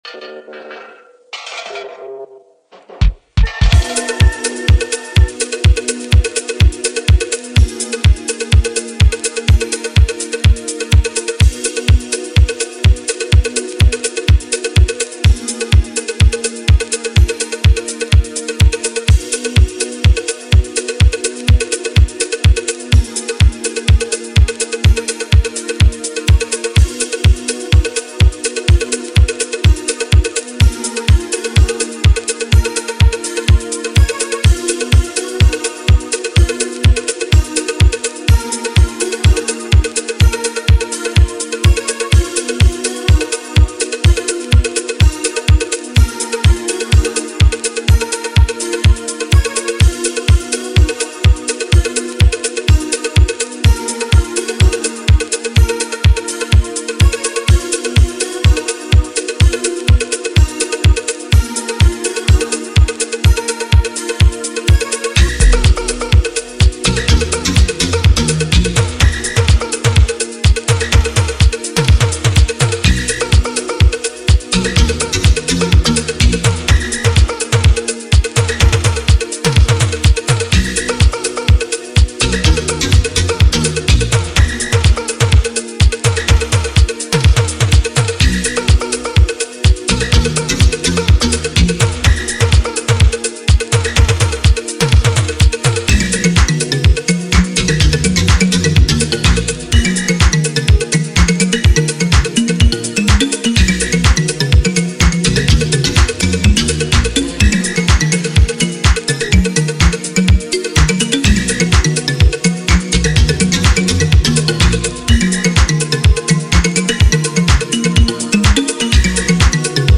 A little background music